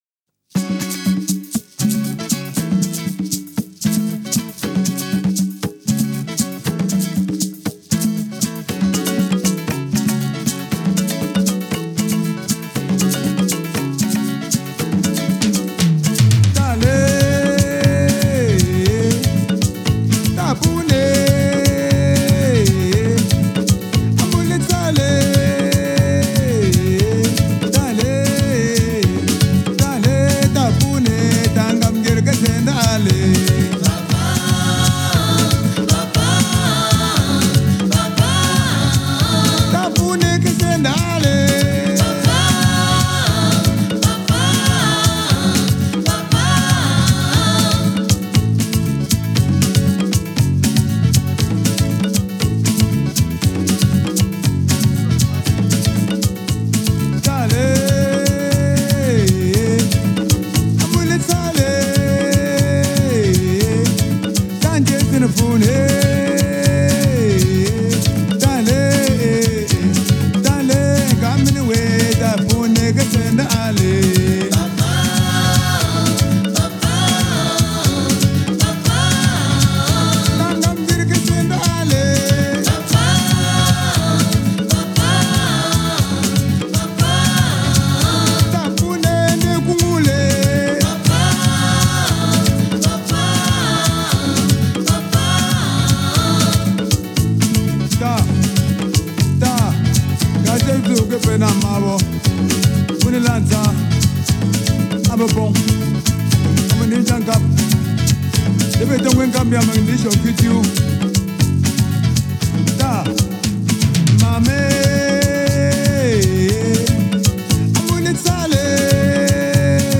Genre: Africa, World